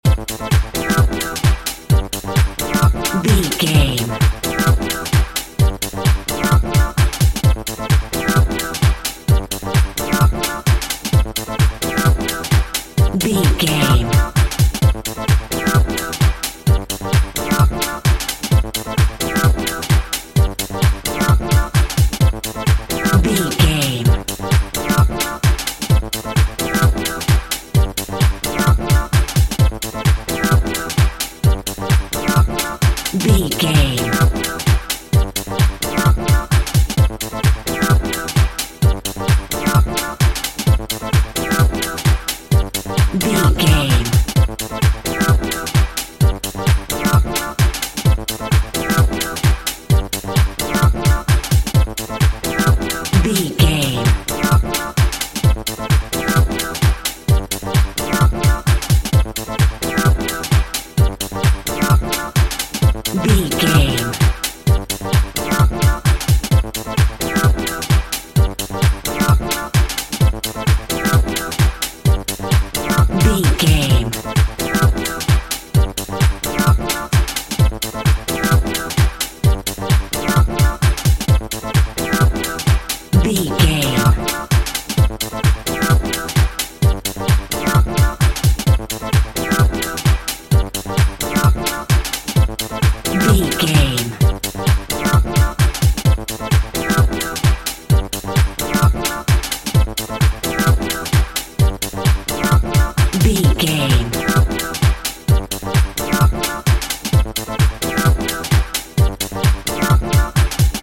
House Music for Sweden.
Atonal
Fast
driving
energetic
uplifting
futuristic
hypnotic
drum machine
synthesiser
electro house
synth lead
synth bass